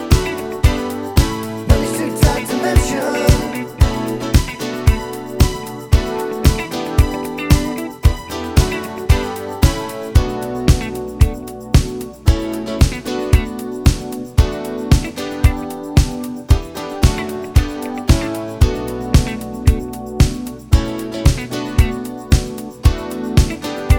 Minus Bass Pop (1980s) 4:12 Buy £1.50